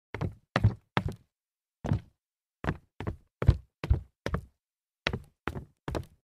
دانلود صدای کفش هنگام راه رفتن 3 از ساعد نیوز با لینک مستقیم و کیفیت بالا
جلوه های صوتی